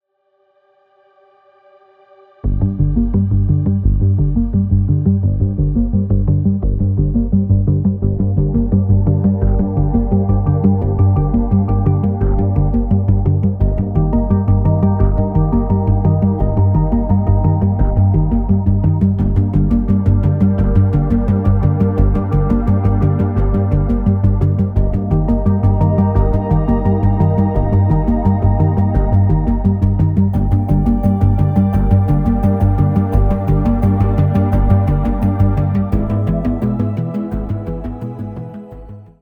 blending electronic sounds and acoustic strings